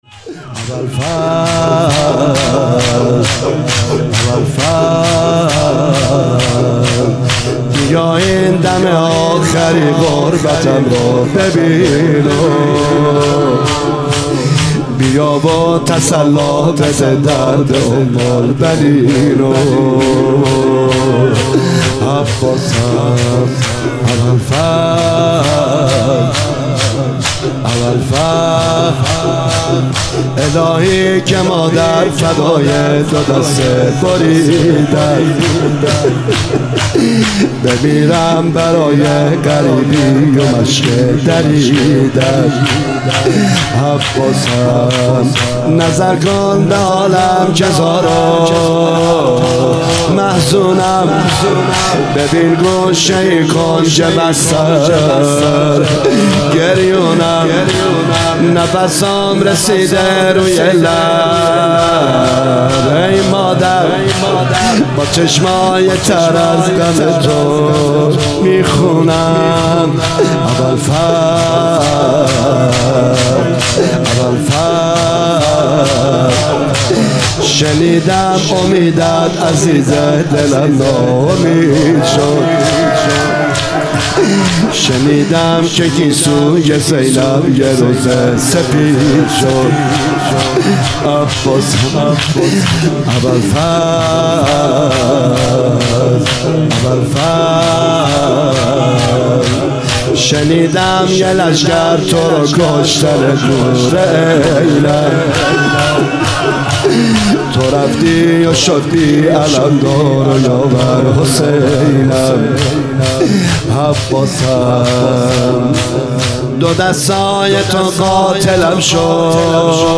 Download 📥 شور | بیا این دم آخری غربتم رو ببین